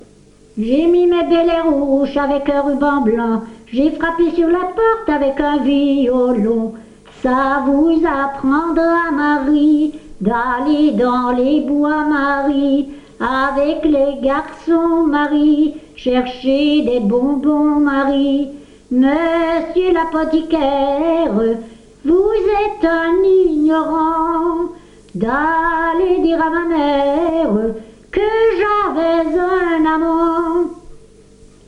Genre : chant
Type : chanson de saut à la corde
Lieu d'enregistrement : Lessines
Support : bande magnétique